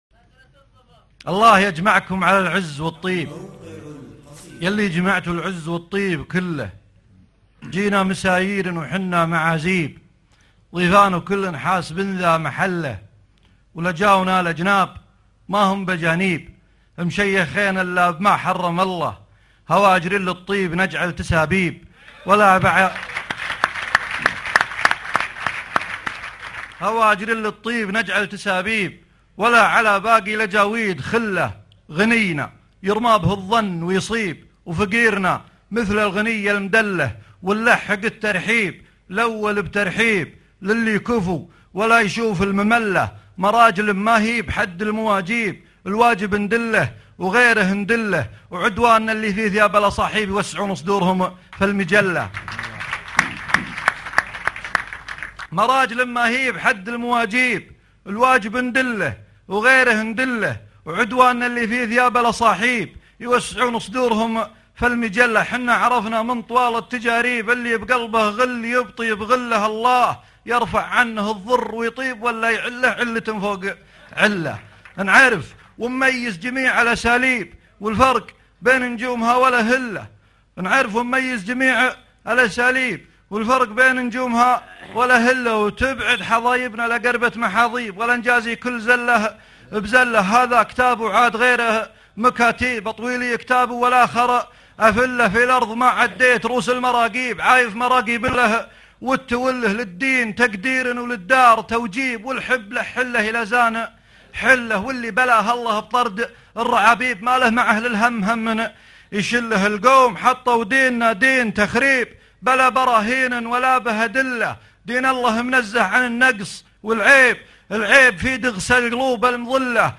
الاصــحايب - مهرجان أهل القصيد السادس 2011   30 نوفمبر 2011